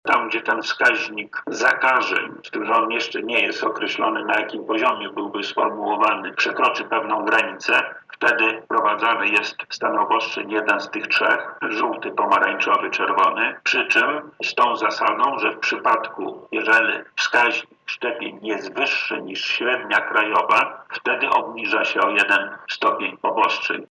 Nie ukrywam, że spodziewam się wprowadzenia obostrzeń – mówi wojewoda lubelski Lech Sprawka: